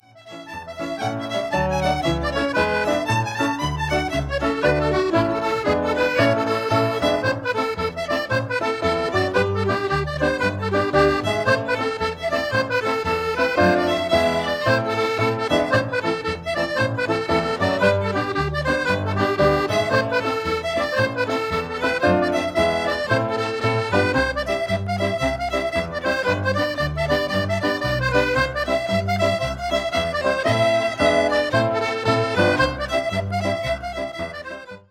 accordion
fiddle
piano
bass